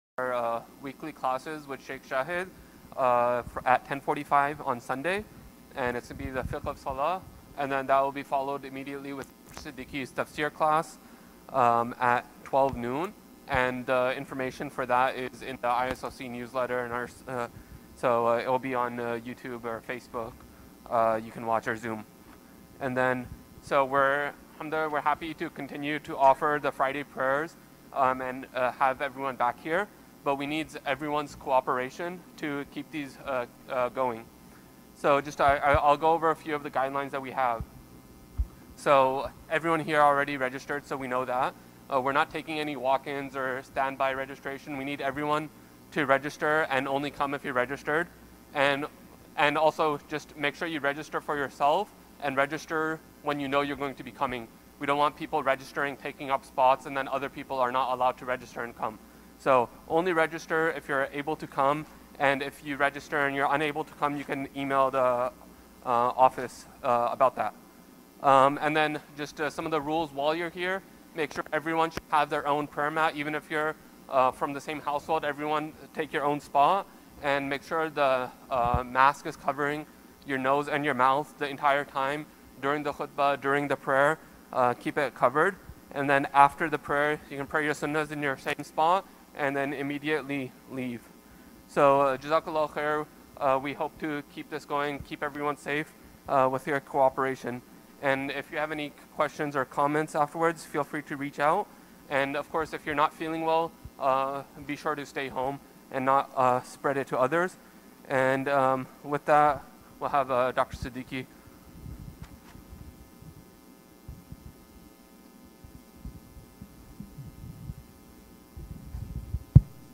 Friday Khutbah - "Truth and Truthfulness"